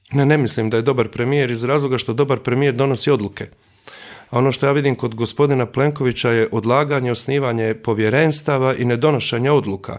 ZAGREB - U ekskluzivnom intervjuu za Media servis predsjednik Mosta i potpredsjednik Sabora Božo Petrov govorio je o slučaju Agrokor ali i Vladi Andreja Plenkovića.